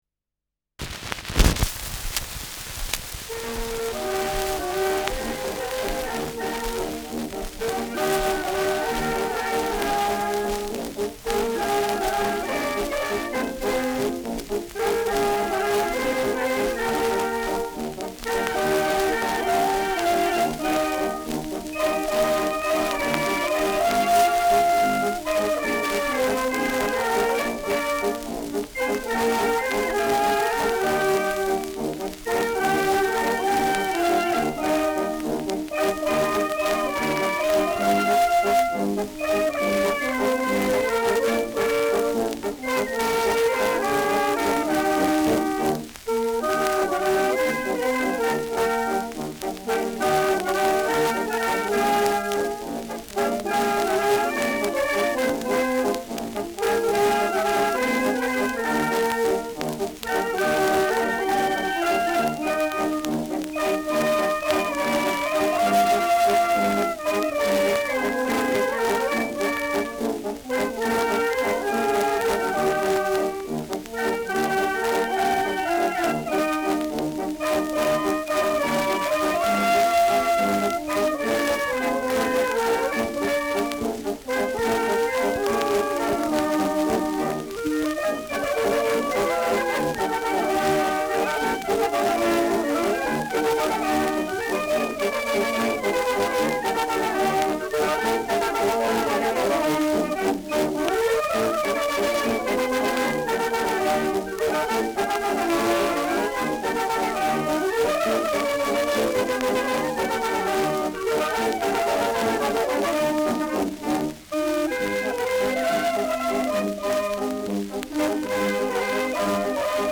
Schellackplatte
Tonrille: Kratzer 2 / 9-10 Uhr Stärker
präsentes Rauschen